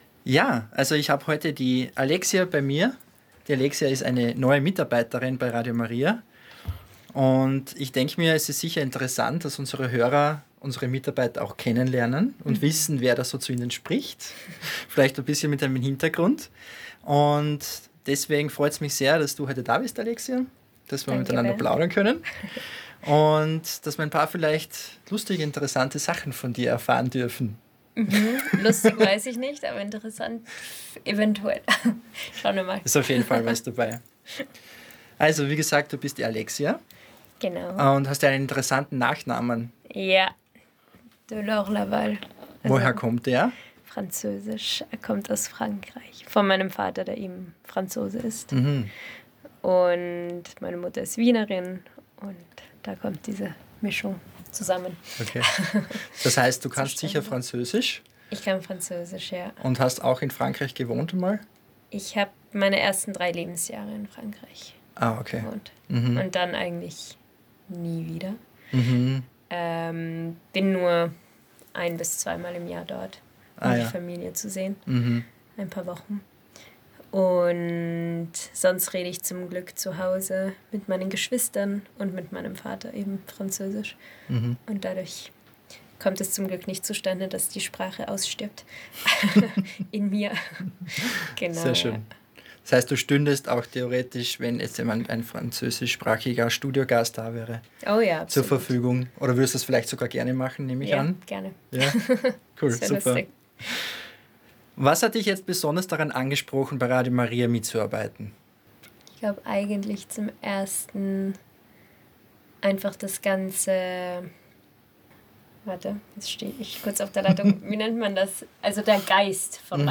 So erste Worte aus dem Interview.